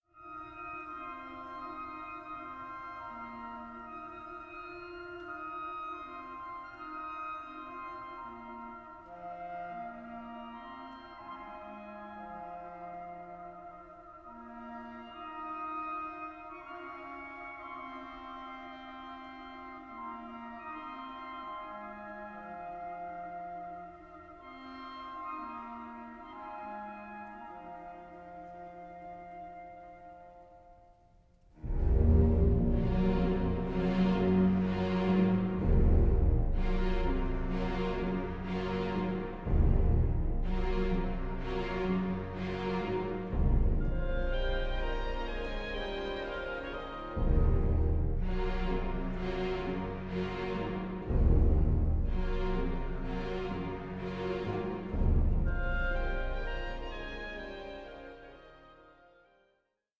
barbaric and savage music